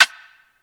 RIM (R.I.P. SCREW).wav